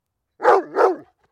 dog.mp3